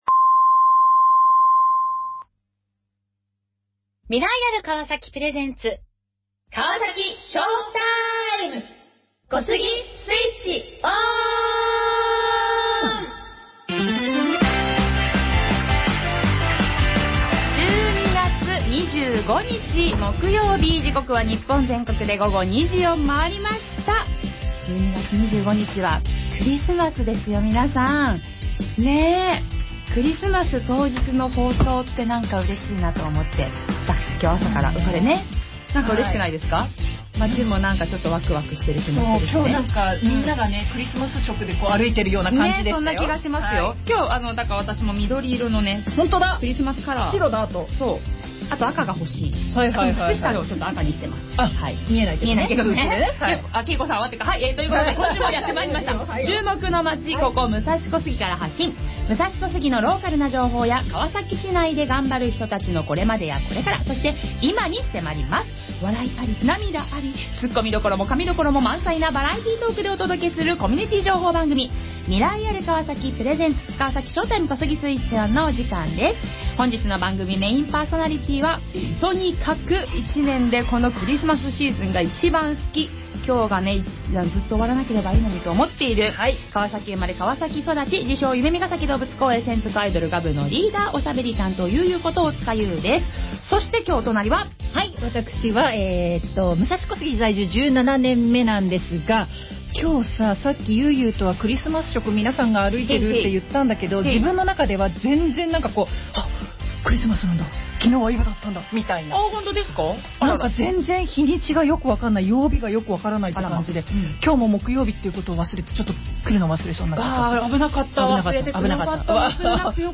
2025年、みなさんはどんな年でしたか？今年の振り返りや来年の抱負など、リスナーのみなさんもぜひお便りでクリスマス忘年会にご参加ください♪クリスマスソングの生歌も歌っちゃうかも！？もちろんいつもの通り、川崎の「今」の情報や、防災情報もお届けします！